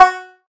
noteblock_banjo.wav